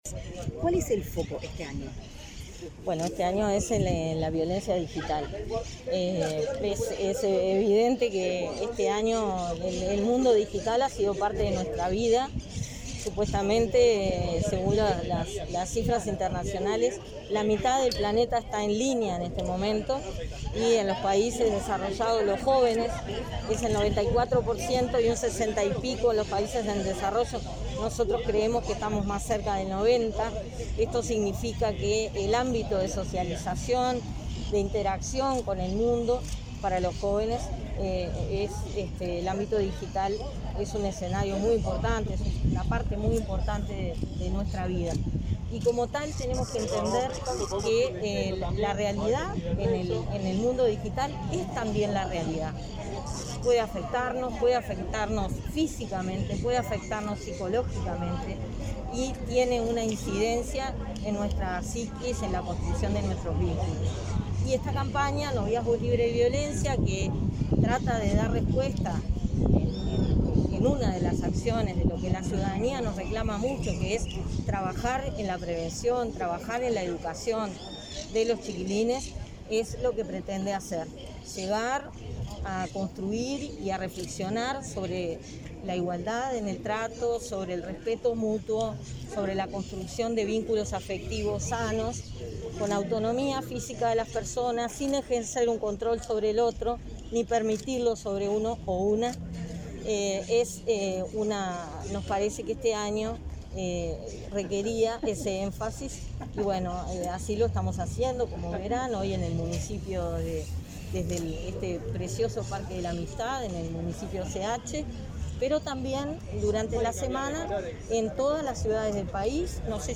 Declaraciones a la prensa de la directora de Inmujeres, Mónica Bottero